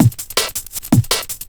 .wav 16 bit 44khz, Microsoft ADPCM compressed, mono,
Lmstep.wav Breakbeat 34k